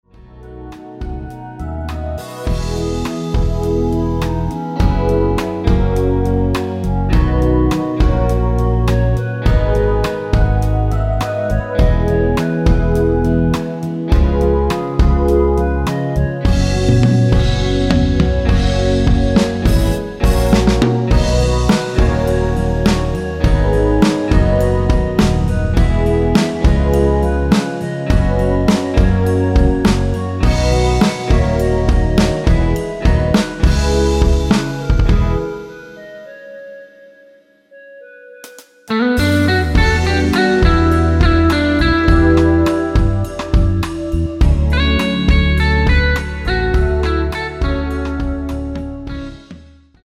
원키에서(-2)내린 멜로디 포함된 MR입니다.(미리듣기 확인)
앞부분30초, 뒷부분30초씩 편집해서 올려 드리고 있습니다.
중간에 음이 끈어지고 다시 나오는 이유는